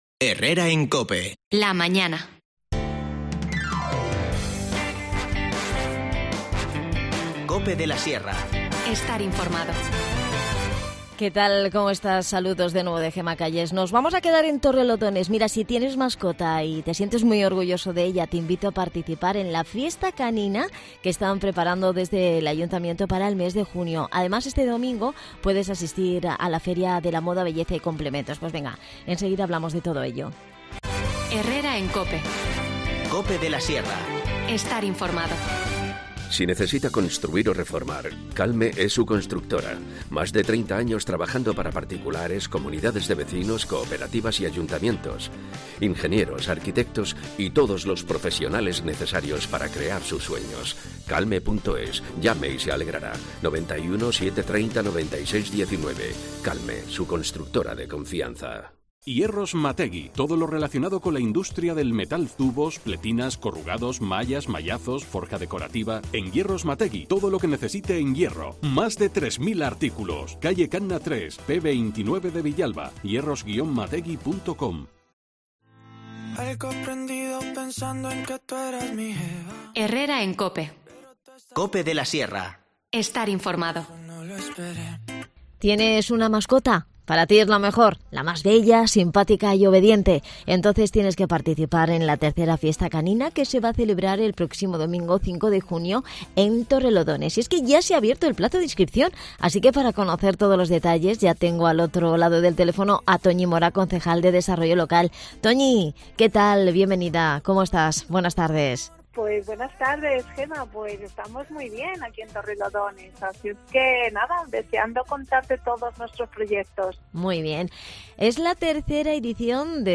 Charlamos de todo ello con Toñi Mora, concejal de Desarrollo Local.